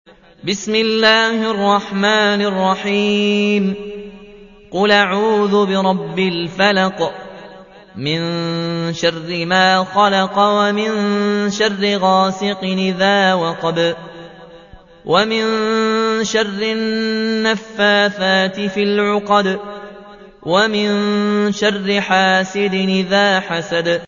تحميل : 113. سورة الفلق / القارئ ياسين الجزائري / القرآن الكريم / موقع يا حسين